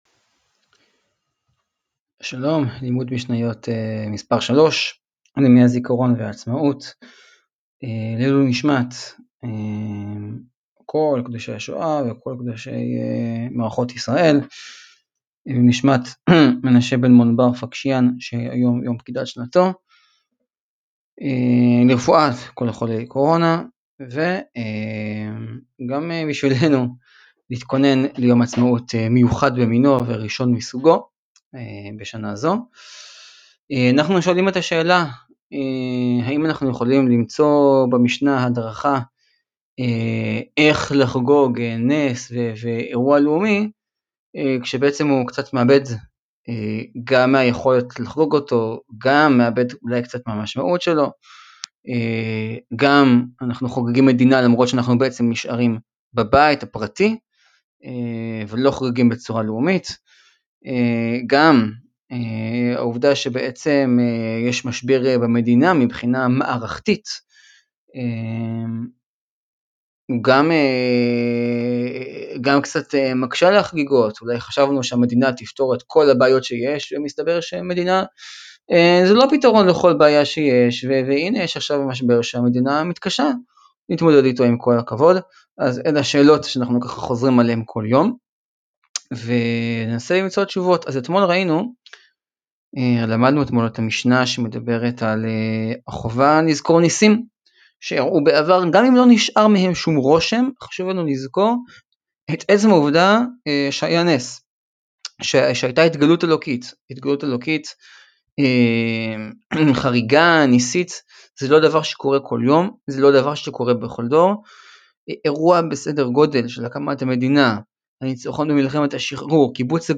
לימוד יומי בשאלה הנשאלת לראשונה מזה 72 שנה: האם יש משמעות לחגיגת עצמאות לבד בבית?